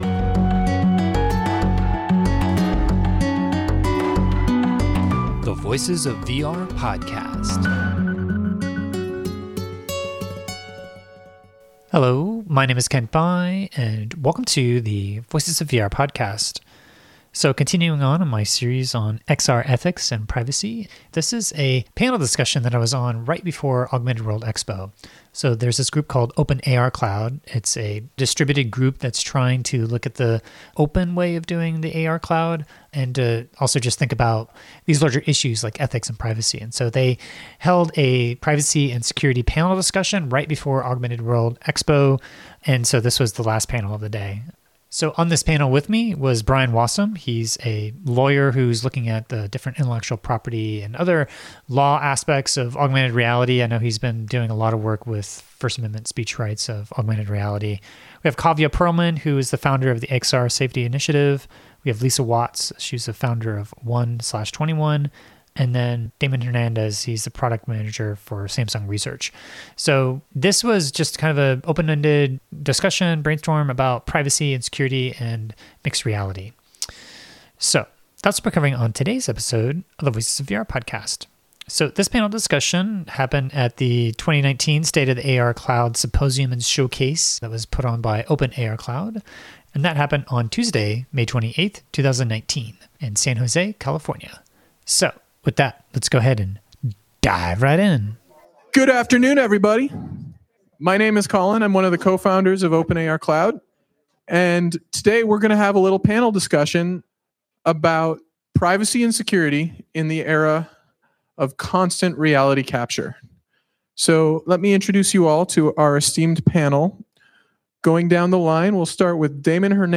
Open AR Cloud held it’s first symposium before the start of Augmented World Expo this year, and I participated on a panel discussion on XR Privacy & Security which included the following panelists: